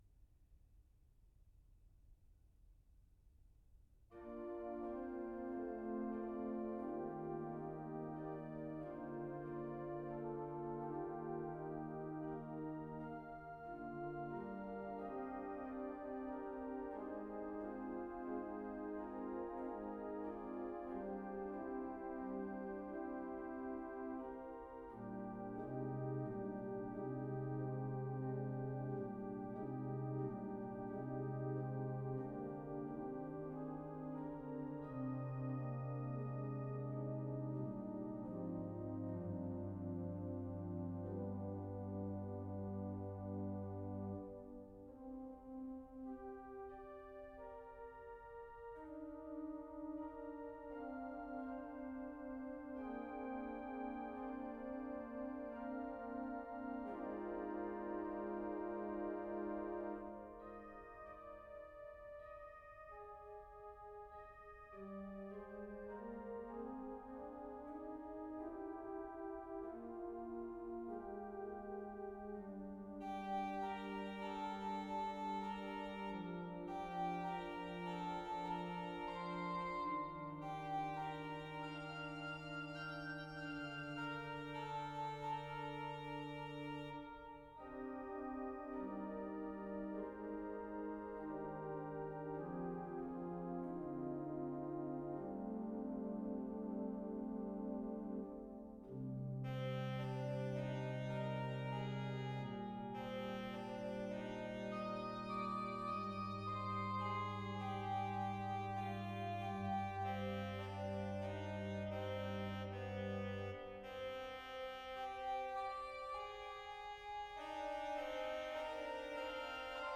organ Duration
The work is a tender and hushed lullaby.